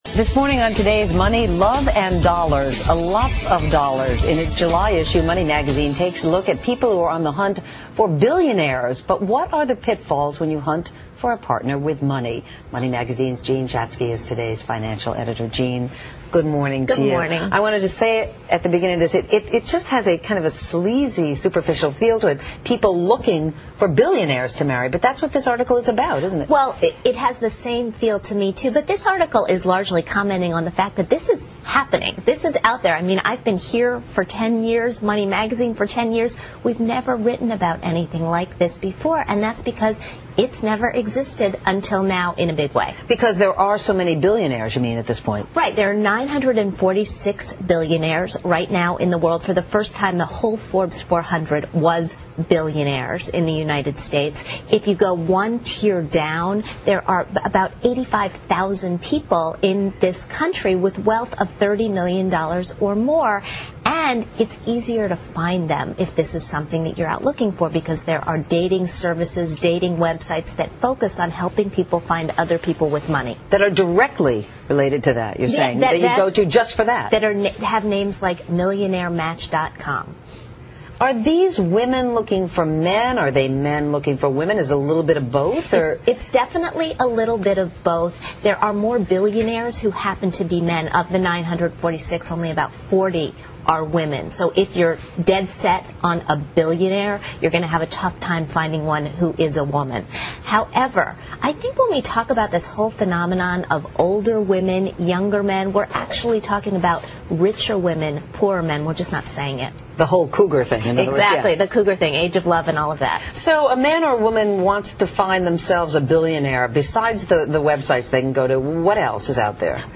访谈录 Interview 2007-07-11&07-13, 我要嫁个有钱人 听力文件下载—在线英语听力室